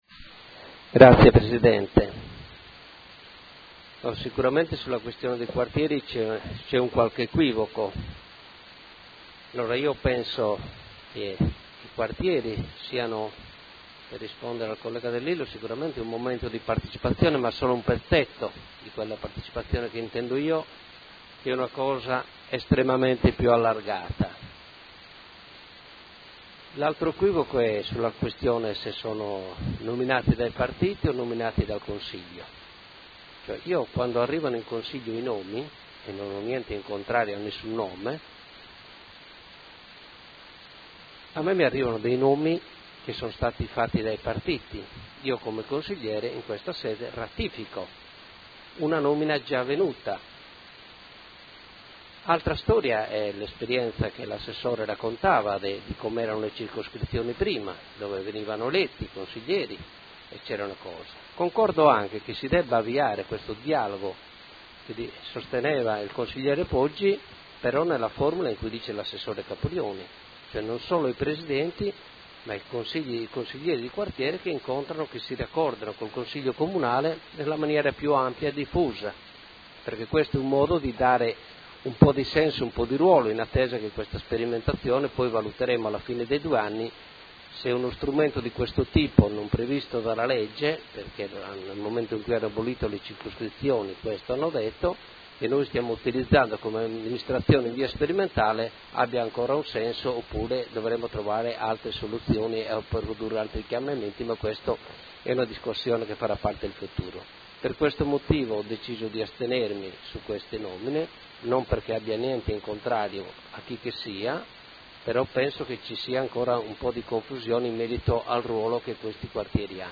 Seduta del 22 ottobre. Proposta di deliberazione: Consiglieri dimissionari dei Quartieri 1 e 3 – nomine nuovi componenti. Dichiarazioni di voto